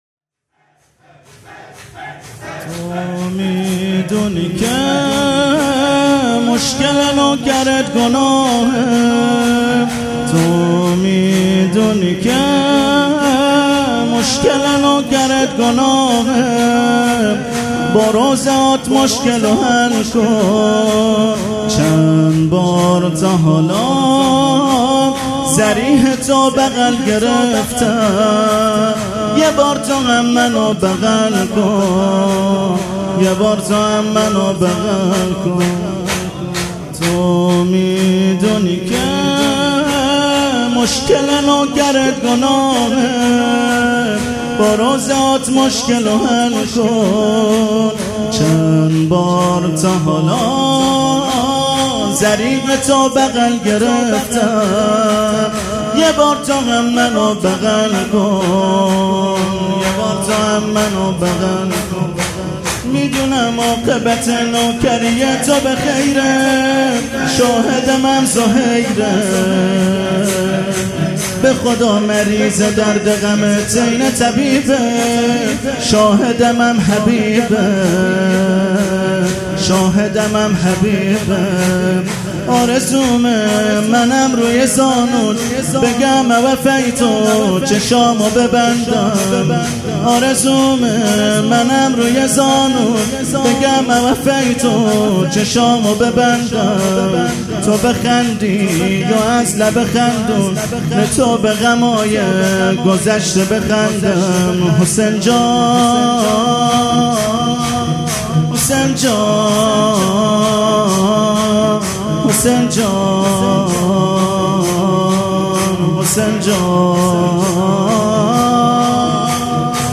مراسم مناجات شب بیست و دوم ماه رمضان
مداح
حاج سید مجید بنی فاطمه